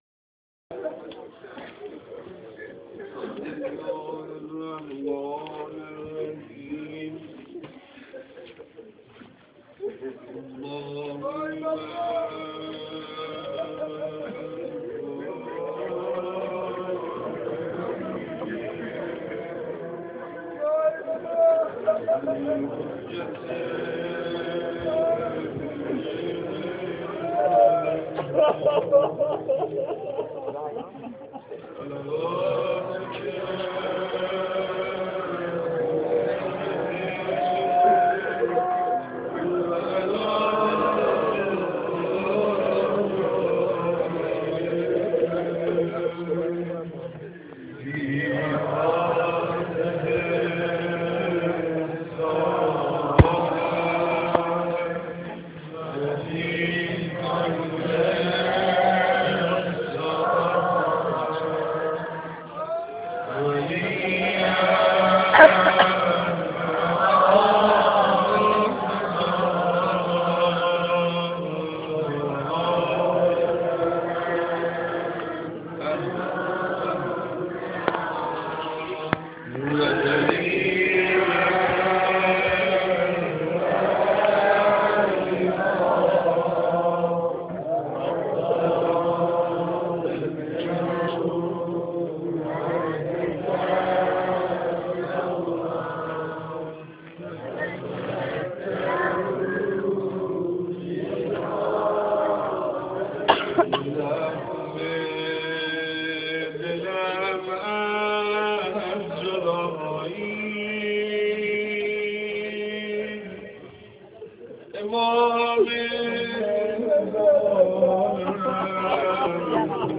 روضه-خوانی3.amr